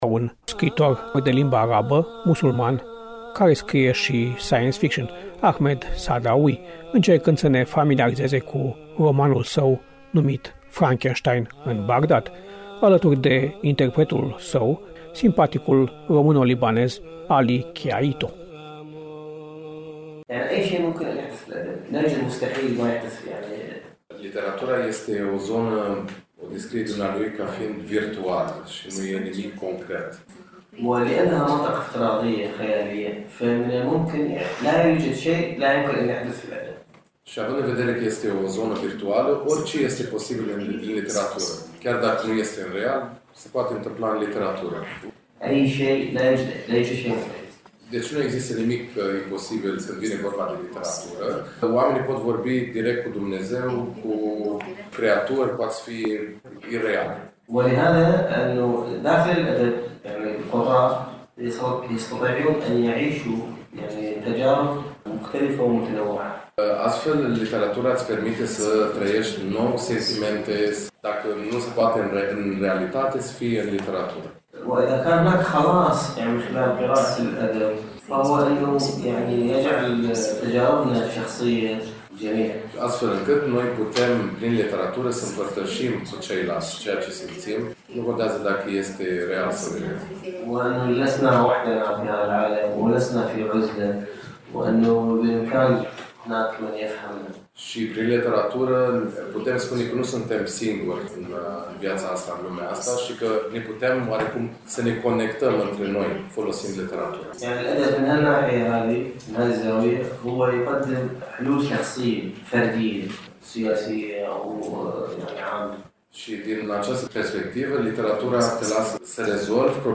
Kilometrul Zero al Festivalului, Piața Unirii, “Casa FILIT”…